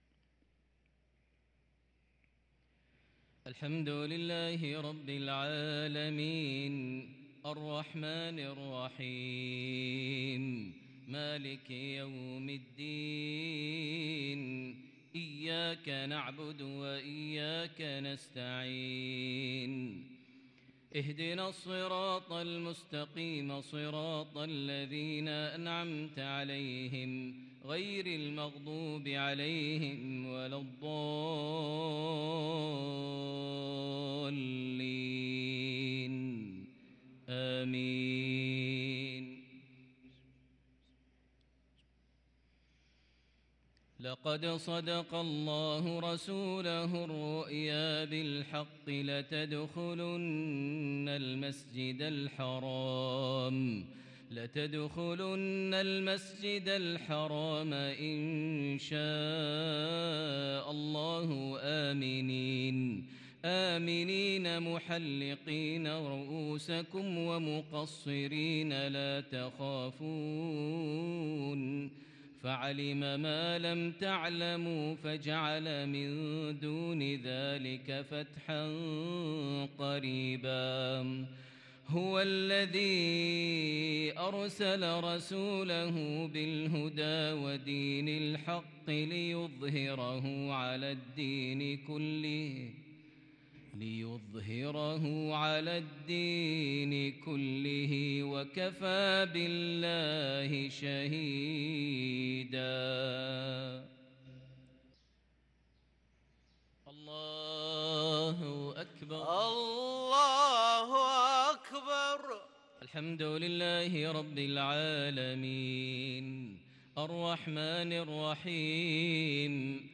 صلاة المغرب للقارئ ماهر المعيقلي 12 صفر 1444 هـ